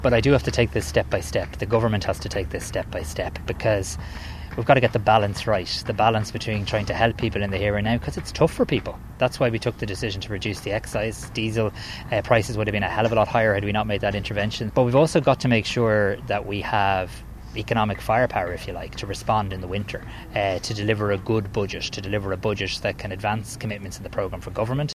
Simon Harris says it would be ‘foolish’ to rule it out;